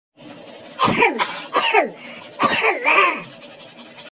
ahem ahem ahmm Meme Sound Effect
ahem ahem ahmm.mp3